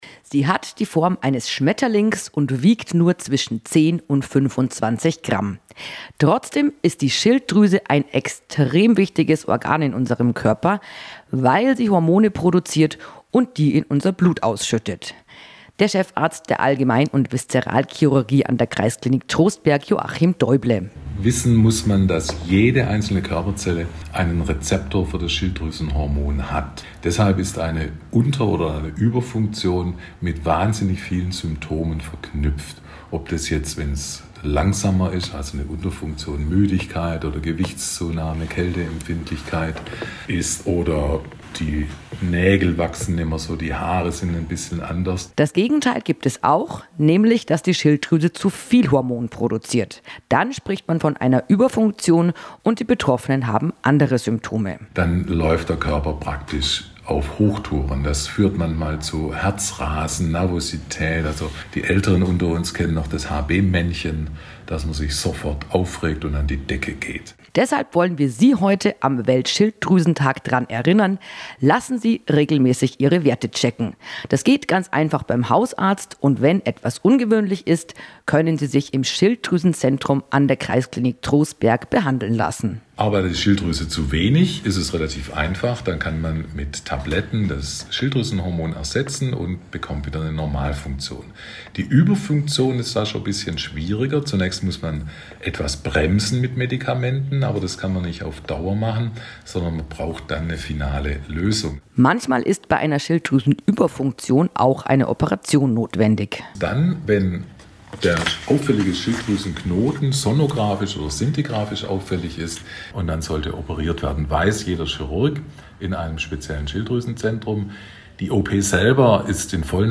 Im Format „Gesundheit KOMPAKT“ mit der Bayernwelle Südost sprechen Mediziner, Therapeuten und Pflegekräfte über medizinische Themen oder Aktuelles aus den Kliniken Südostbayern AG.